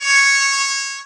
horn01.mp3